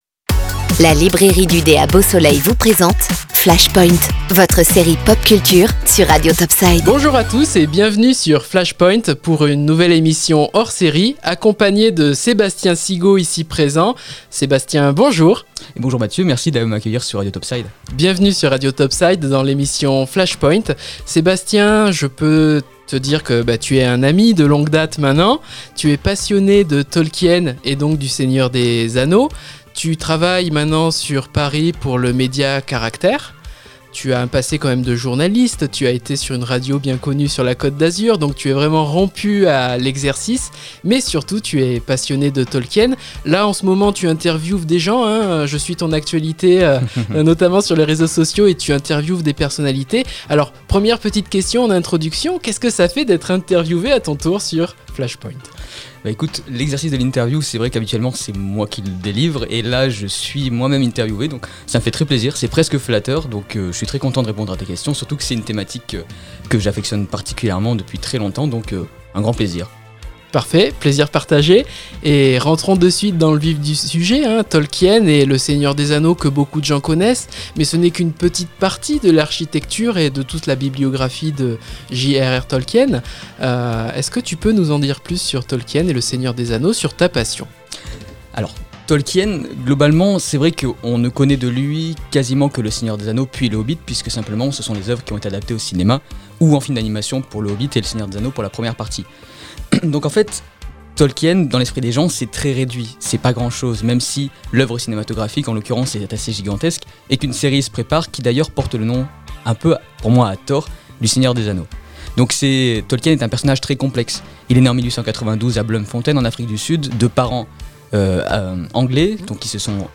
Vidéo intégrale de l interview a retrouver ici